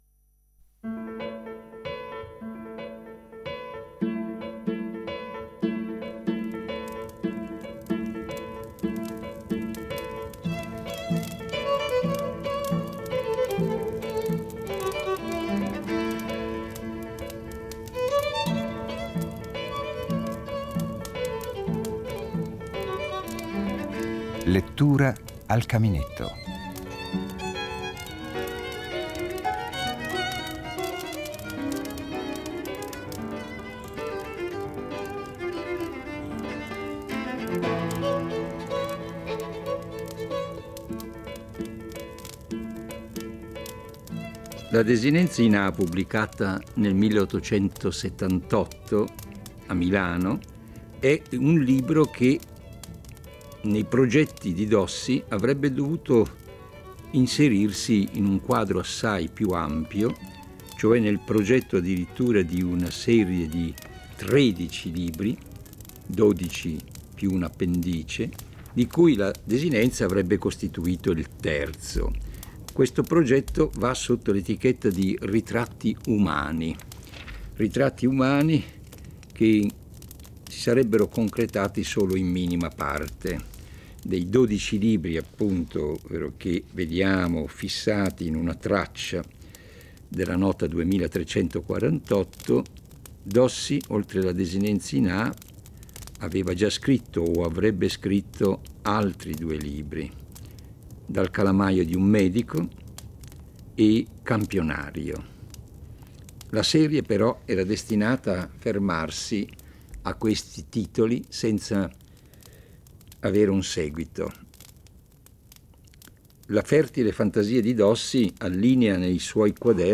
"Lettura al caminetto", 29 ottobre 1995